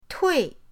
tui4.mp3